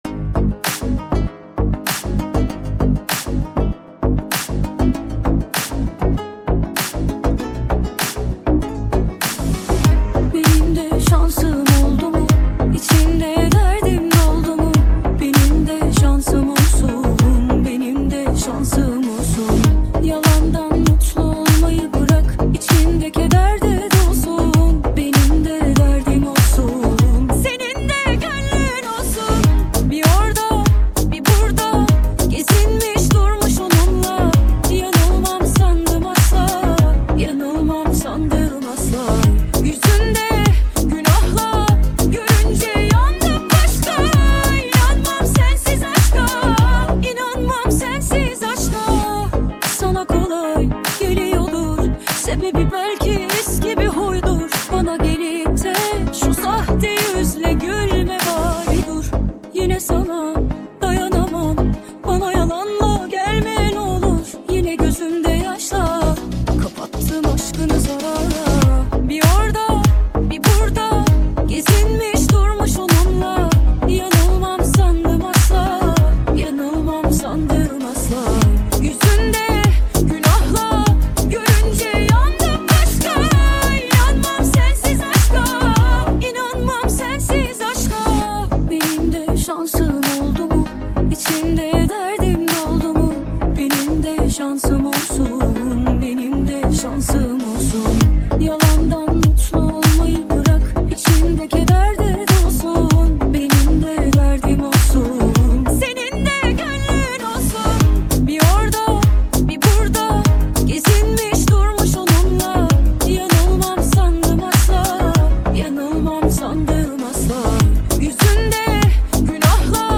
دانلود نسخه ریمیکس همین موزیک